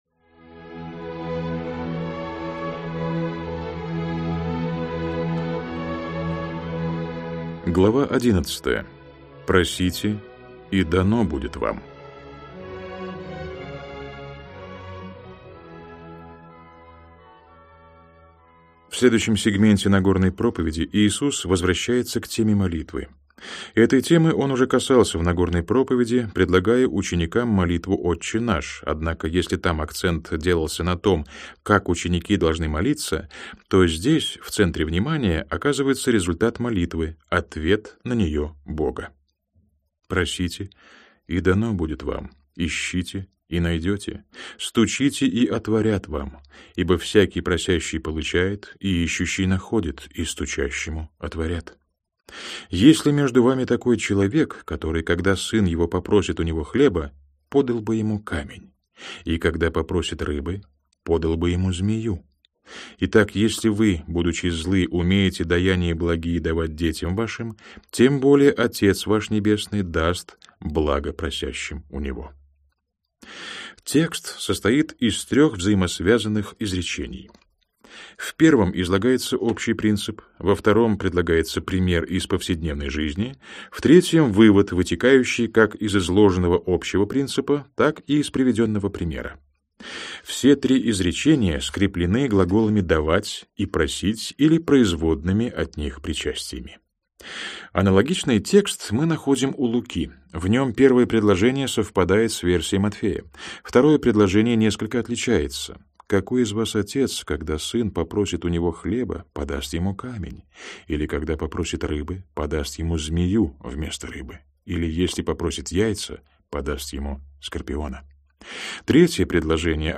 Aудиокнига Иисус Христос.